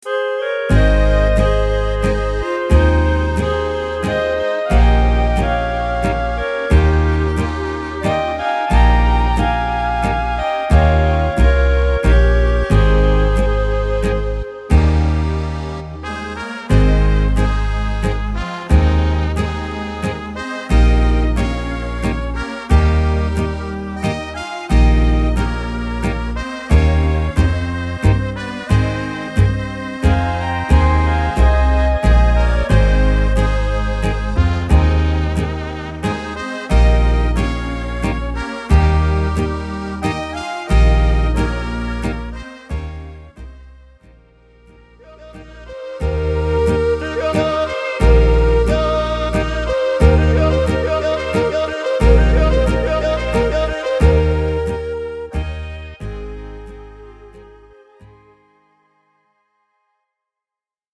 Tempo: 90 / Tonart:  Ab – Dur